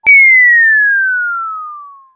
Fall3.wav